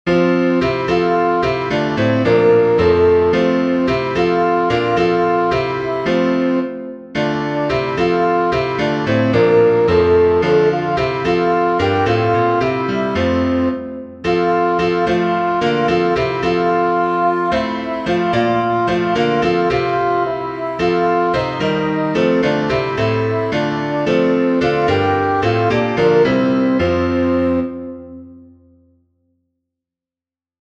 geneva_comfort_comfort_o_my_people-alto1.mp3